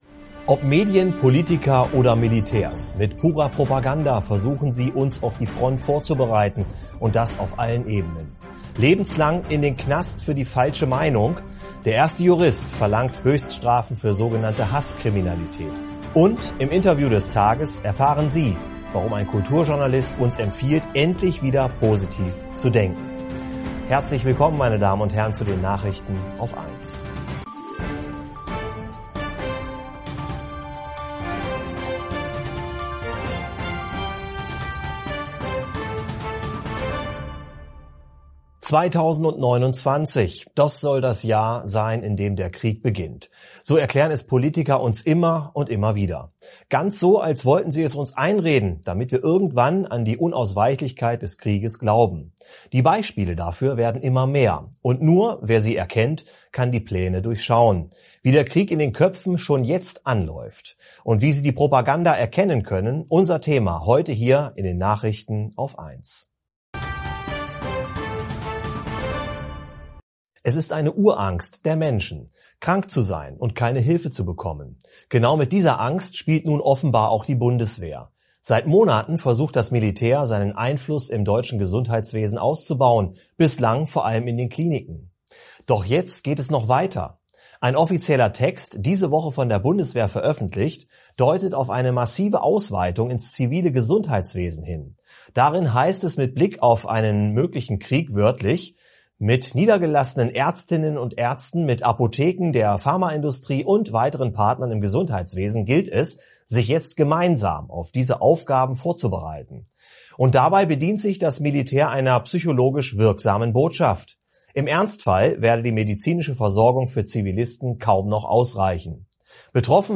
+ Der erste Jurist verlangt „Höchststrafen“ für sogenannte Hasskriminalität + Und im Interview des Tages erfahren Sie, warum ein Kulturjournalist uns empfiehlt, endlich wieder positiv zu denken Mehr